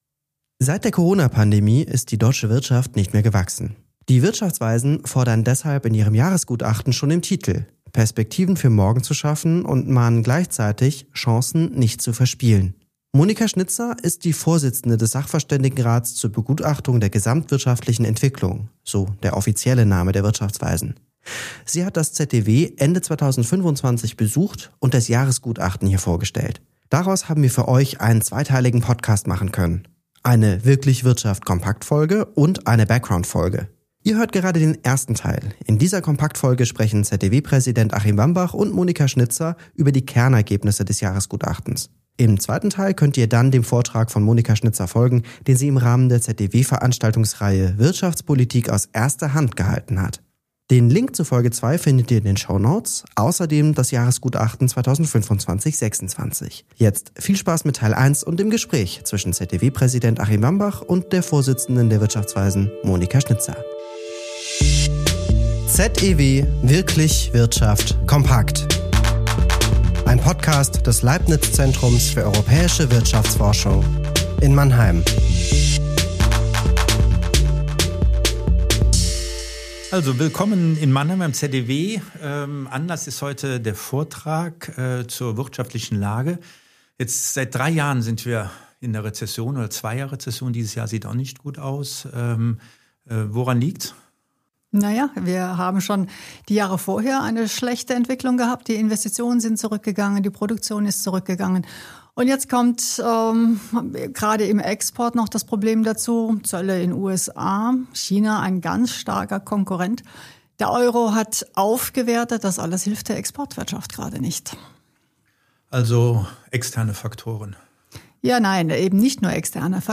Ihr hört gerade den ersten Teil in dieser Kompaktfolge sprechen ZEW-Präsident Achim Wambach und Monika Schnitzer über die Kernergebnisse des Jahresgutachten.